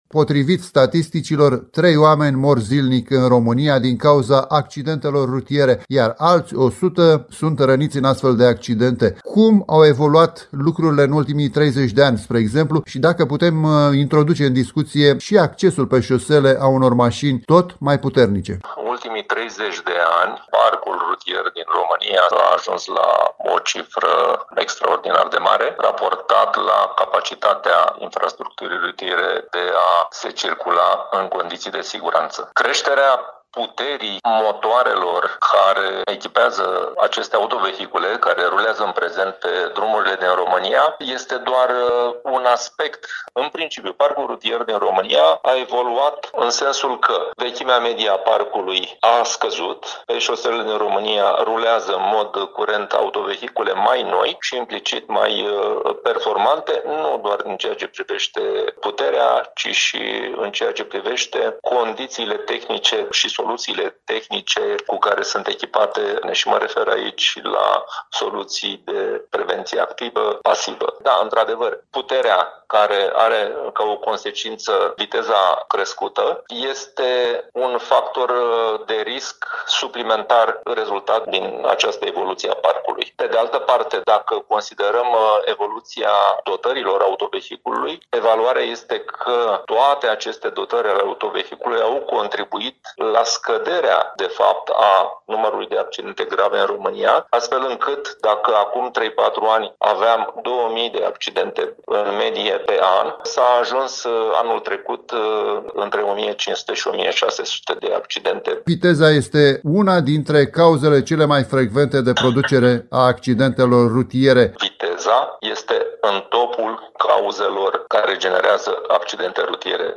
AUDIO | Zilnic, în accidentele rutiere din Romania, mai mult de trei persoane își pierd viața. Interviu